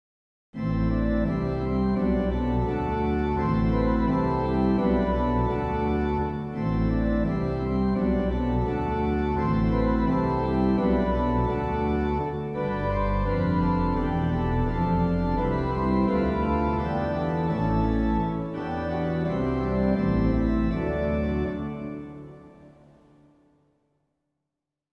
Velikonoční písně
(nápěvy modernější)
mp3-nácvik